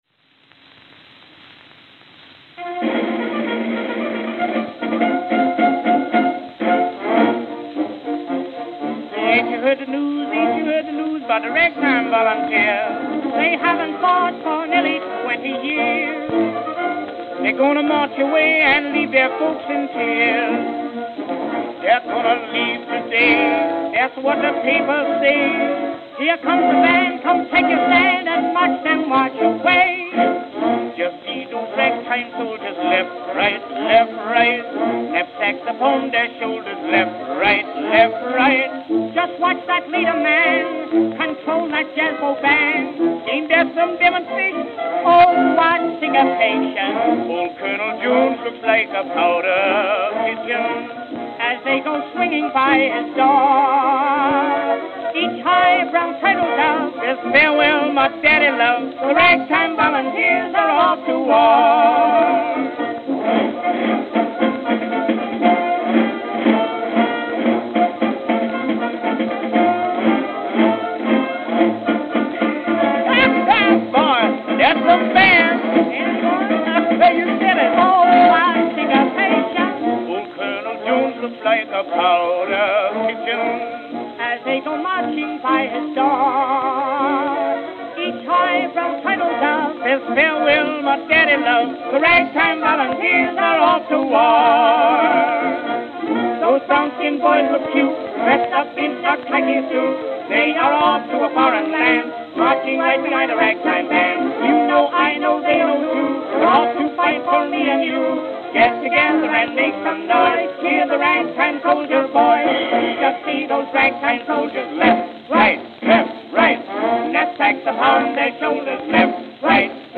Victor 10-Inch Double-Sided Acoustical Records